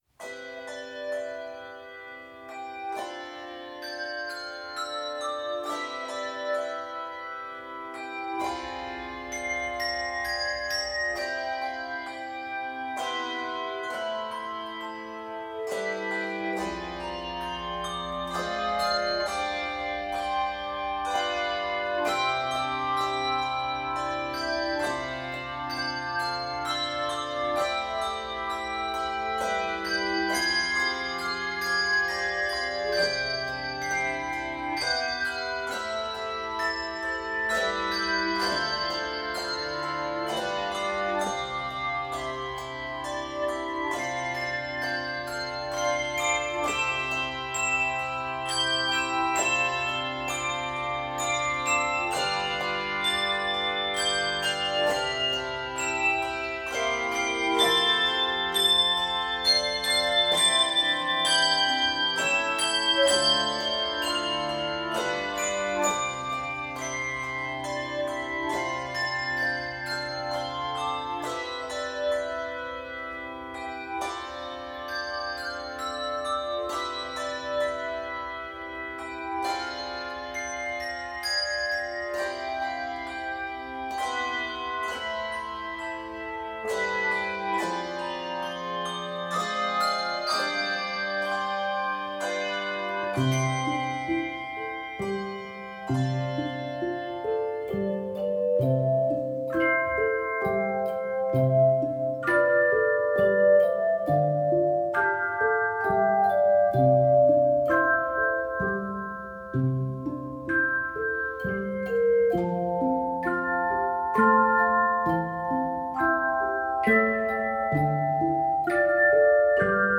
Key of C Major. 66 measures.